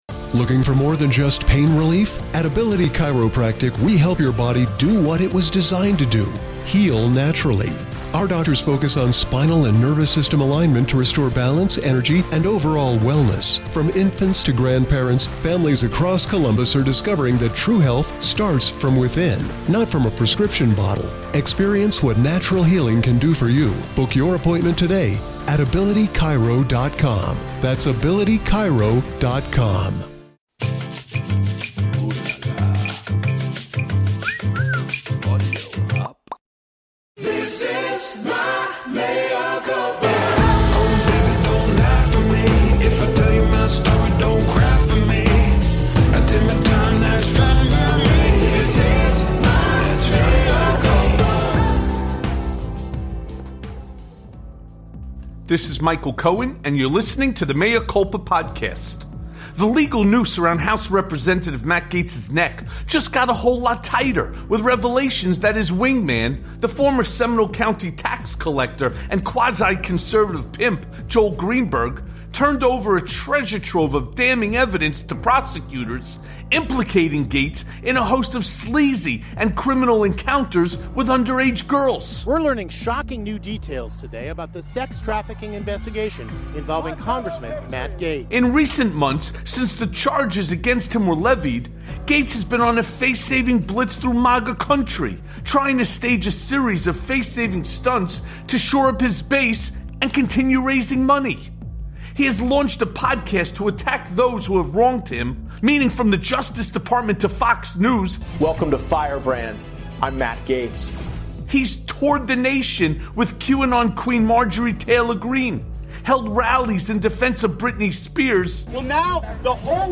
Matt Gaetz’s “Wingman” Releases Damning New Evidence + A Conversation With Andrew Sullivan